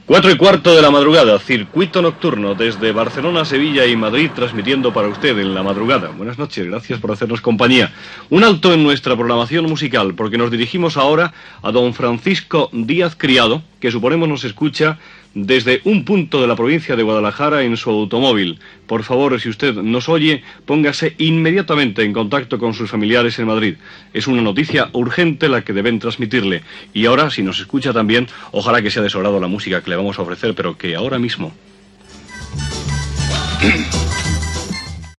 Hora, identificació del programa, avís d'urgència i tema musical